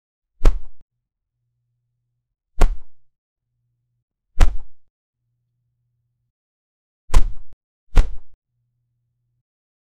punch-thud--wga6hjf3.wav